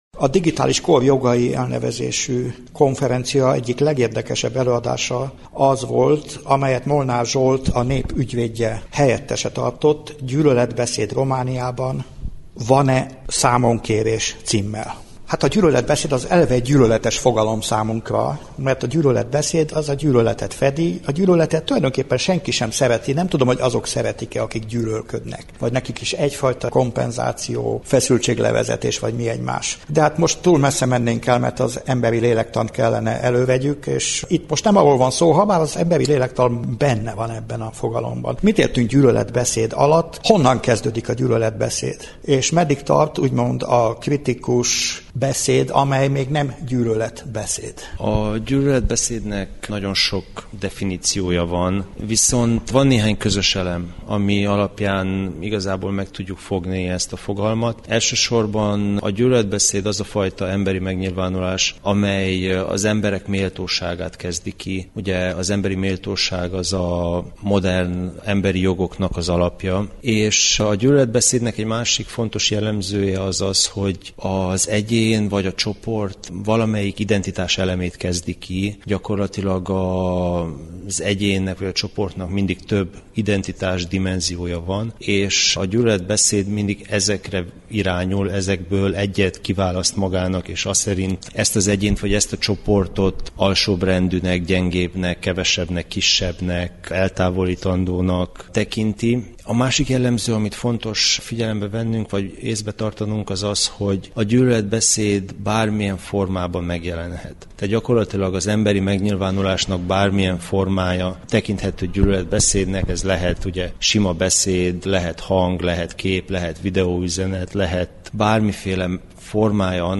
Az esemény során mindhárom előadóval beszélgettünk.